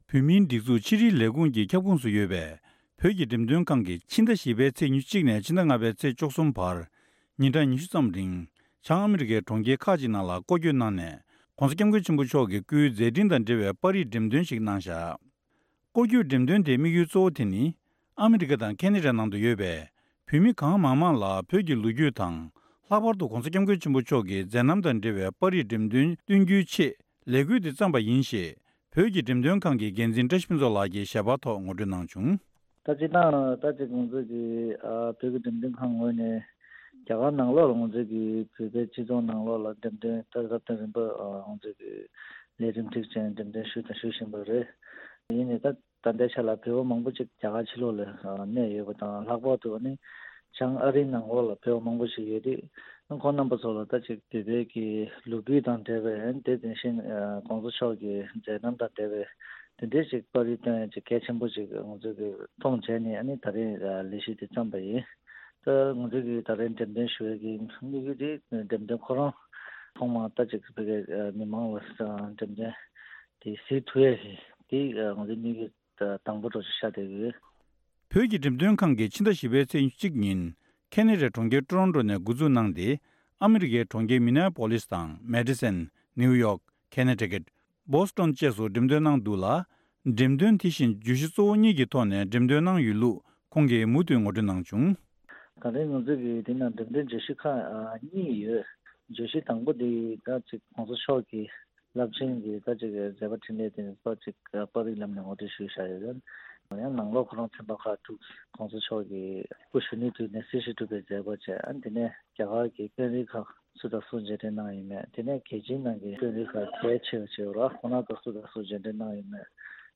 ཞལ་པར་ཐོག་བཀའ་འདྲི་ཞུས་ནས་ཕྱོགས་སྒྲིག་ཞུས་པ་ཞིག་གསན་རོགས་གནང་།